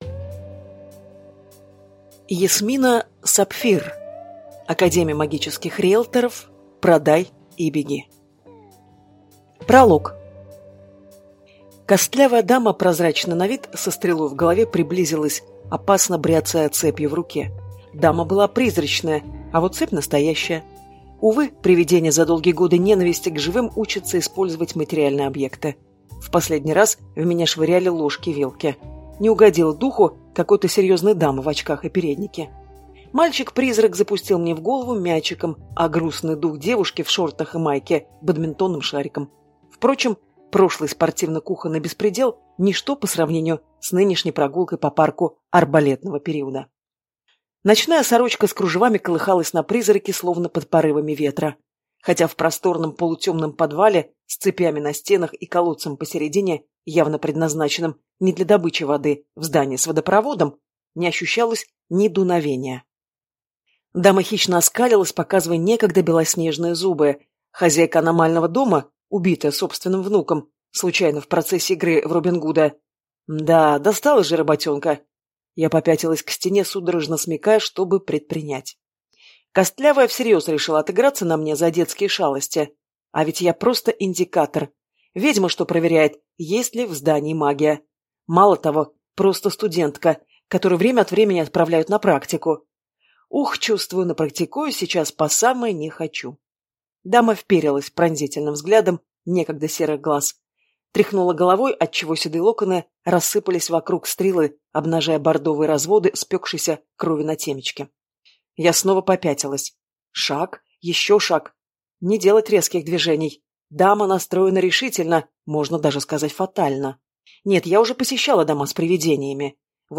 Аудиокнига Продай и беги. Академия магических риэлторов | Библиотека аудиокниг